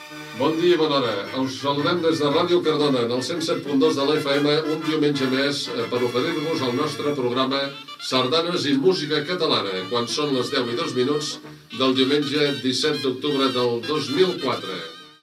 Identificació de l'emissora, hora i data
FM